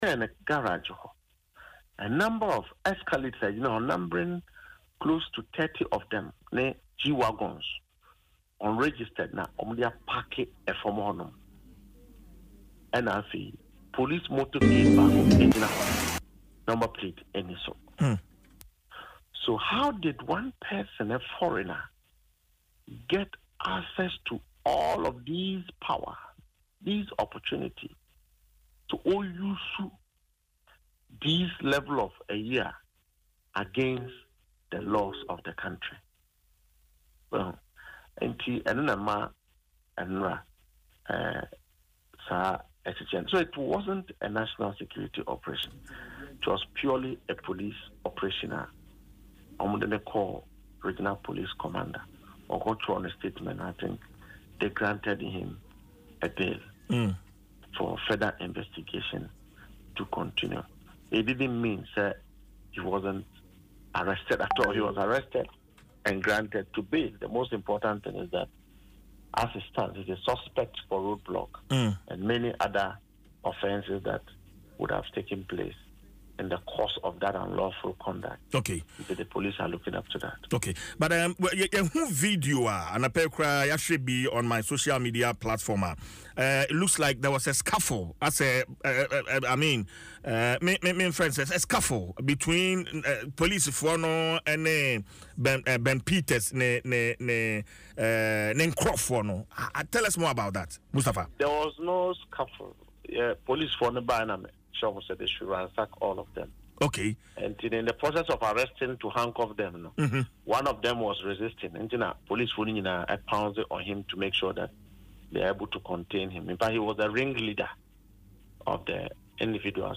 The Deputy Director of Operations at the Jubilee House, Mustapha Gbande confirmed the bail in an interview on Adom FM Dwaso Nsem.